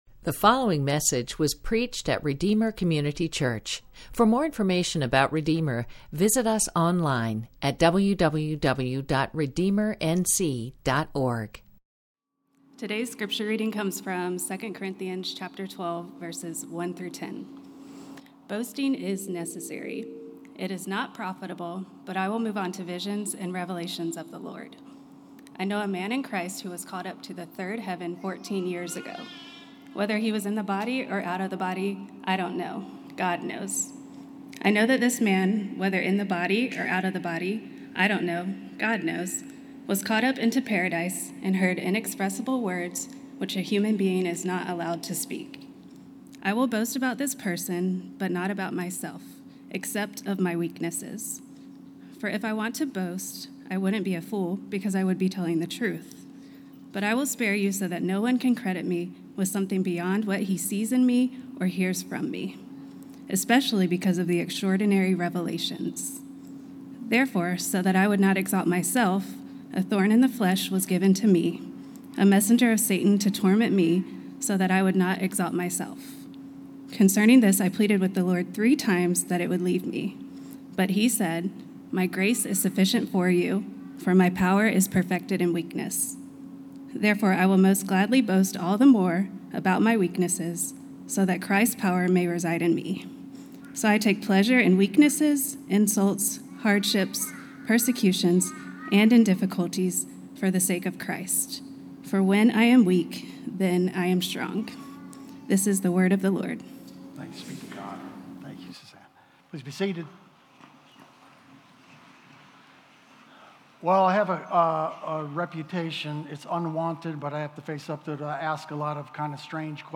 Watch Watch on YouTube Listen on Apple Podcasts Listen on Spotify Subscribe to Podcast Latest Sermon Loading Content...